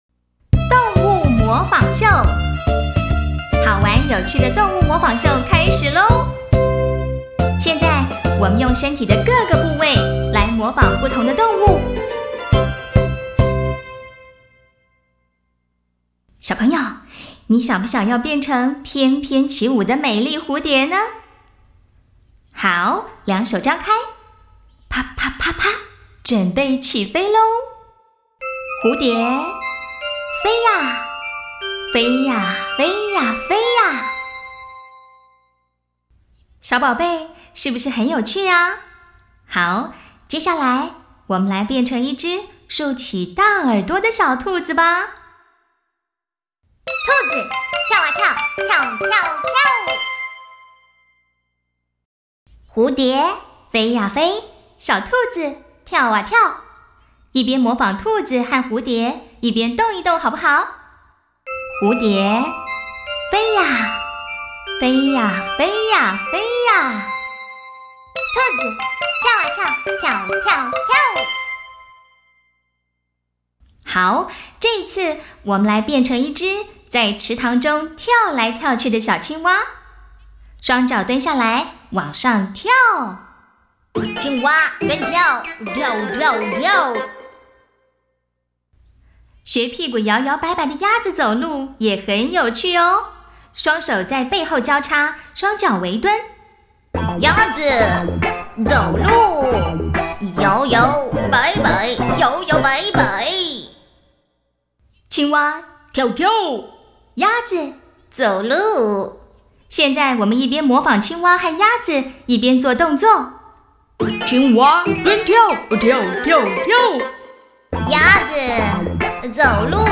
國語配音 女性配音員
總體而言，她的聲音明亮清晰，適合各種動畫、遊戲及廣告配音需求。